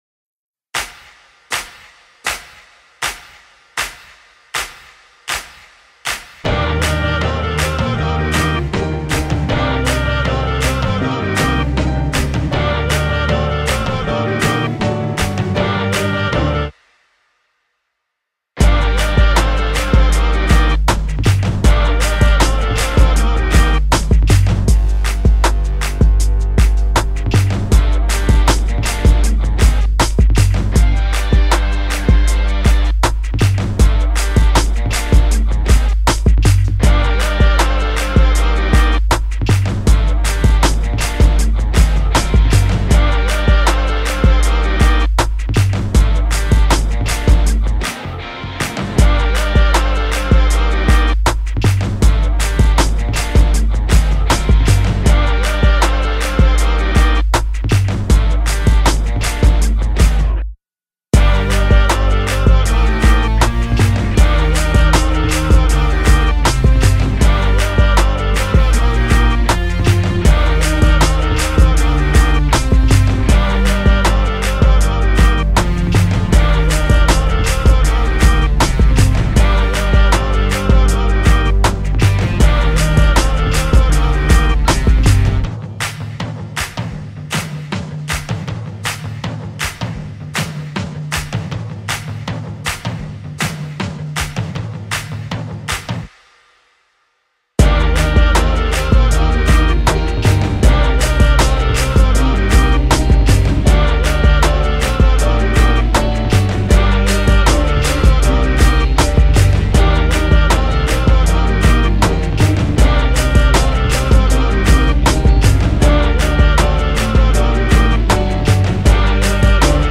Rap Instrumental